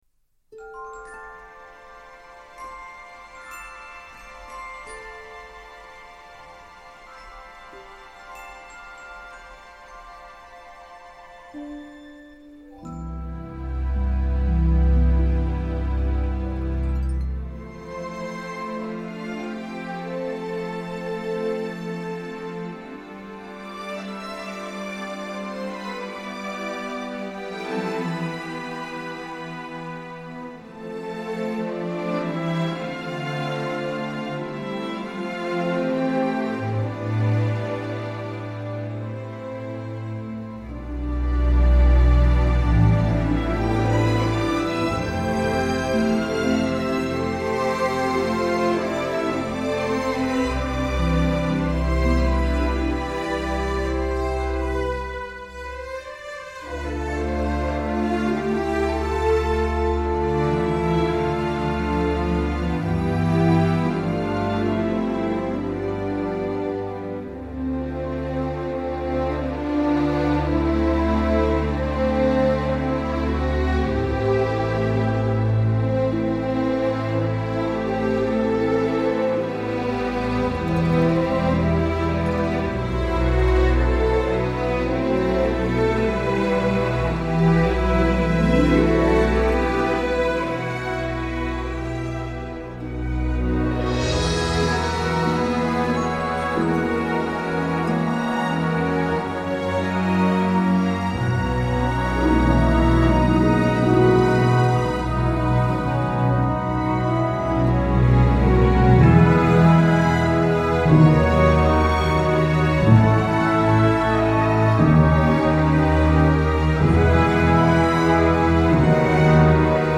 fort agréablement romantique, sans lourdeur ni gros sabot.